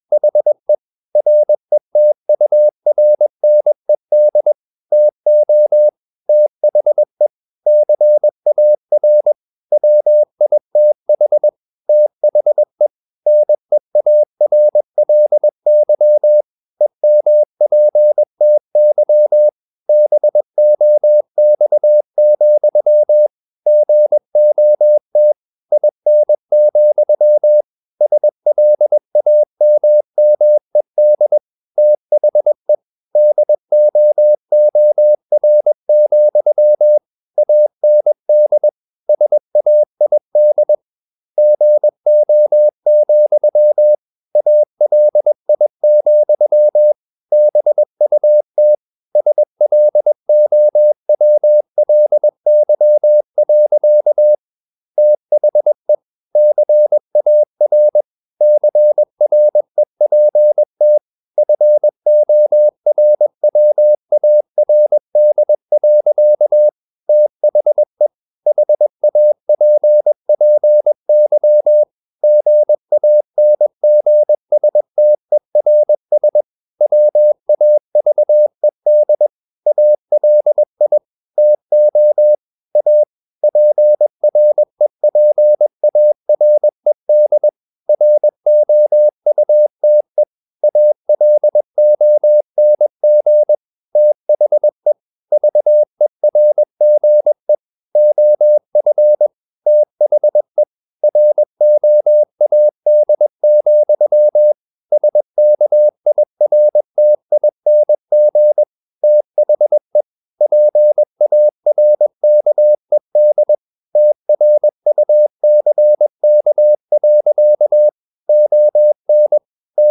Never 21wpm | CW med Gnister
Never_0021wpm.mp3